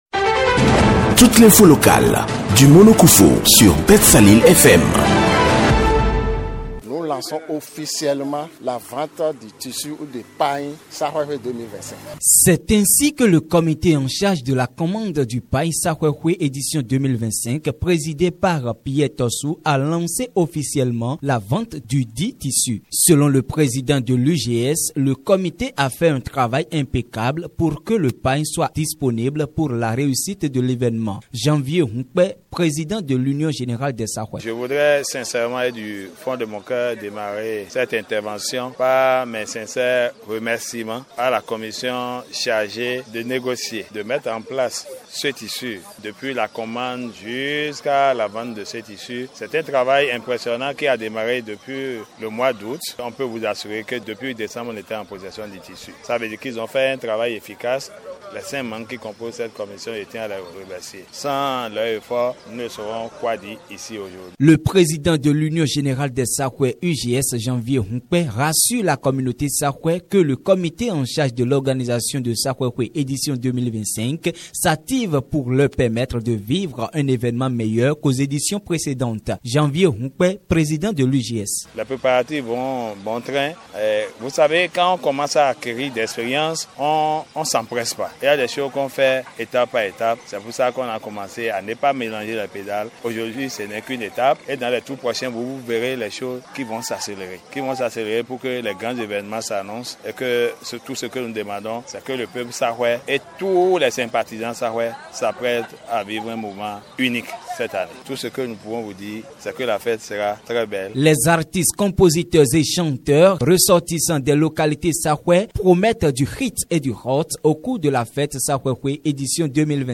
La grande fête identitaire des filles et fils de la communauté Sahouè du Bénin dénommée SAXWE-XWE édition 2025 s’annonce belle. Et pour cause, la vente du pagne de cette fête portée par l’Union Générale des Sahouè (UGS SAXWE-NUKUN) a été officiellement lancée ce dimanche 23 février 2025 à la place publique de Houéyogbé.